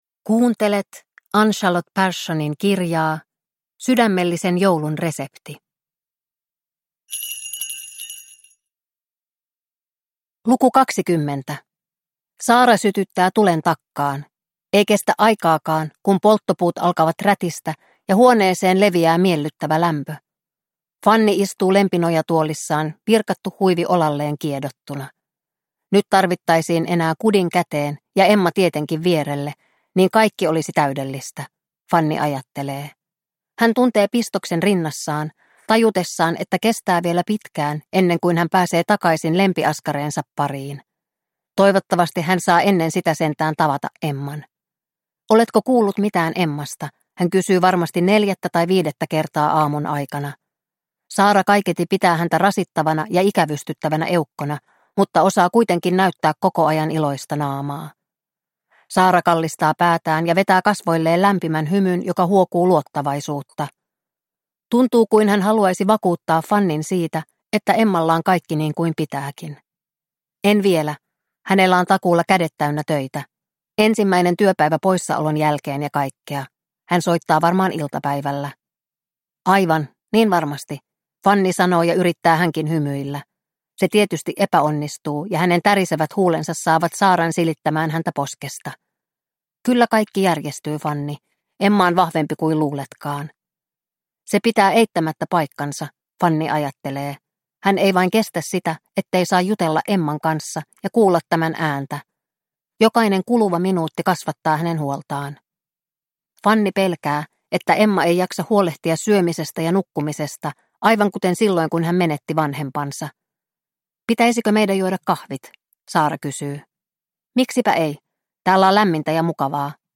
Sydämellisen joulun resepti - Luukku 20 – Ljudbok – Laddas ner